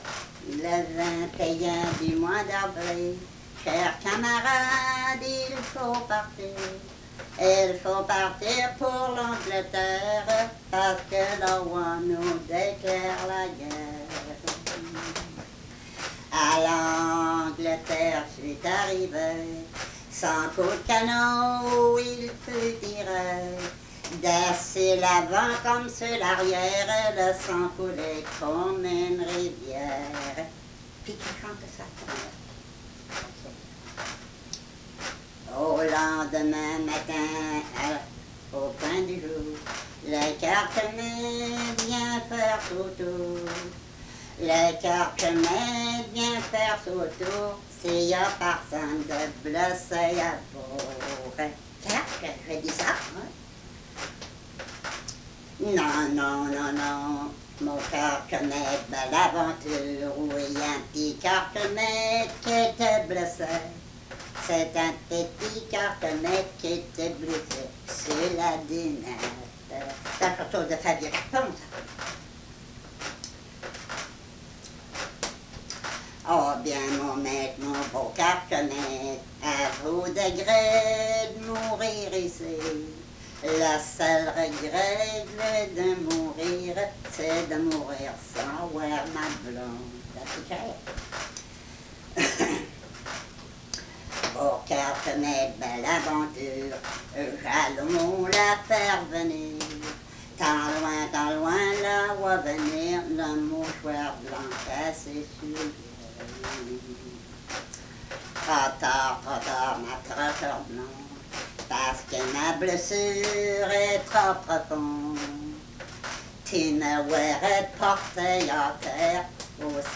des chansons collectées auprès de chanteurs et de communautés francophones à travers toute la province
Emplacement Cap St-Georges